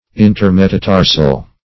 Search Result for " intermetatarsal" : The Collaborative International Dictionary of English v.0.48: Intermetatarsal \In`ter*me`ta*tar"sal\, a. (Anat.) Between the metatarsal bones.